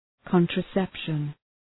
Shkrimi fonetik{,kɒntrə’sepʃən}